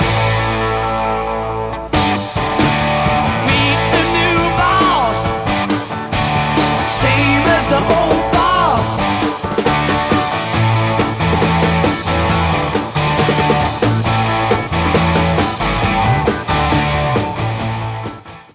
Actually ... that's a pretty slick keyboard tumbao, eh?